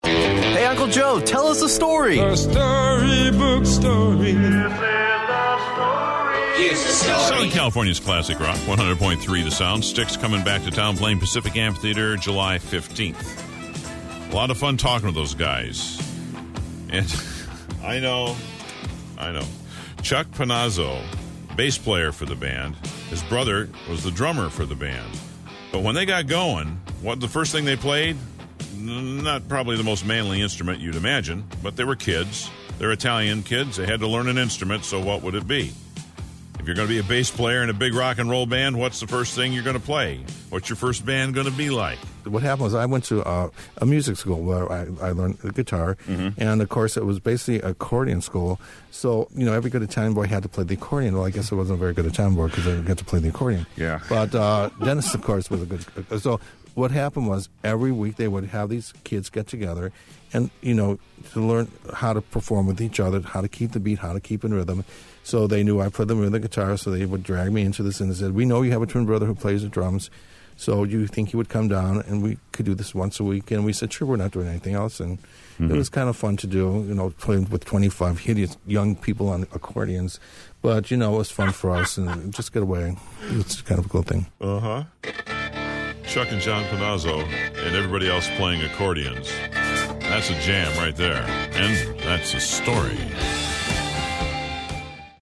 Styx bassist Chuck Panozzo on the first band he & his twin brother John played in together.